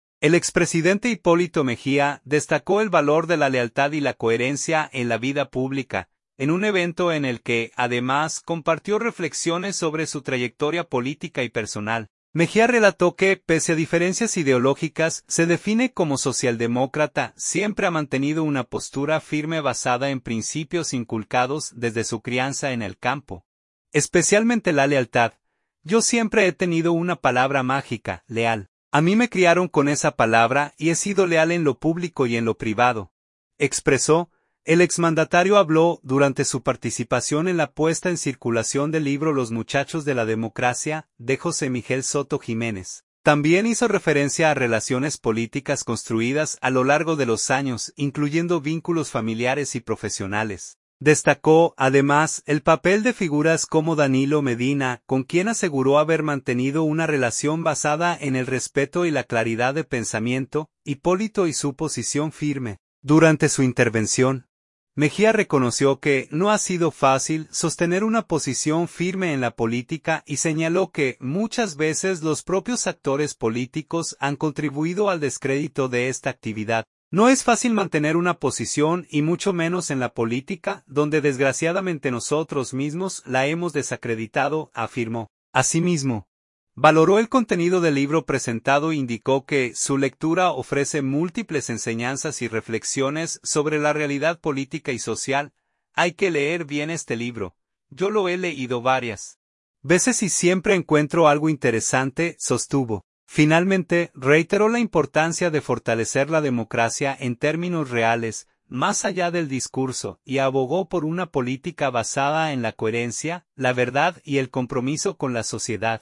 Hipólito Mejía habló durante la puesta en circulación del libro “Los muchachos de la democracia”.